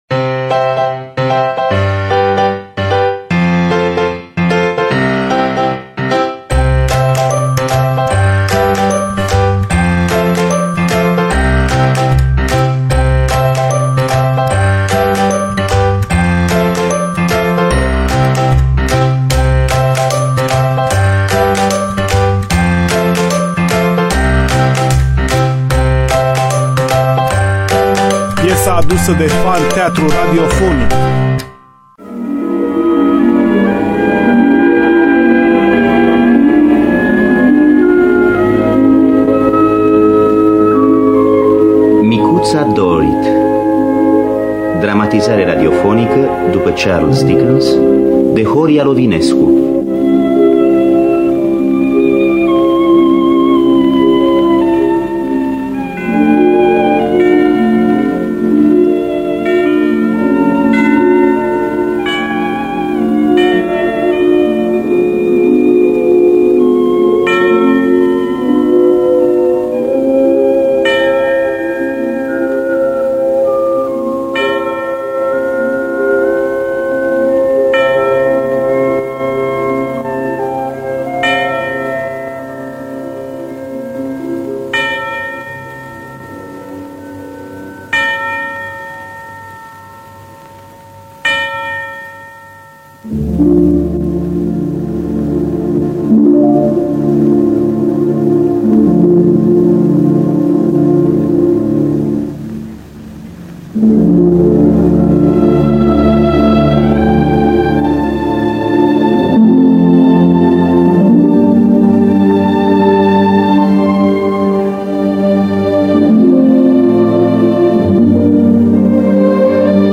Dramatizarea radiofonică de Horia Lovinescu.